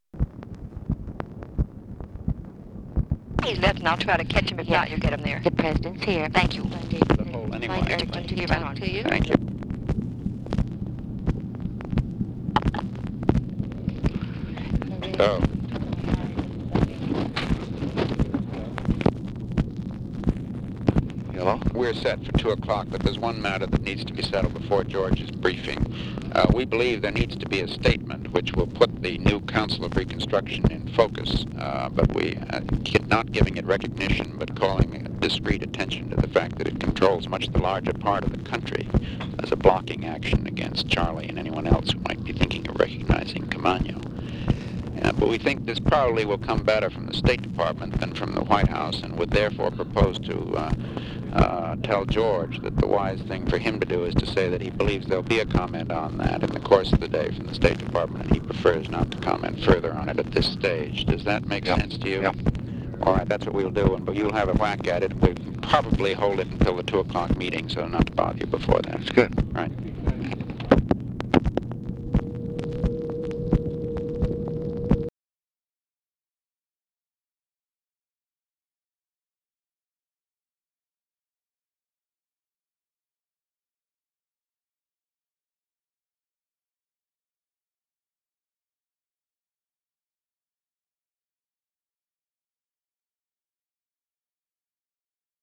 Conversation with MCGEORGE BUNDY, TELEPHONE OPERATOR and OFFICE SECRETARY, May 8, 1965
Secret White House Tapes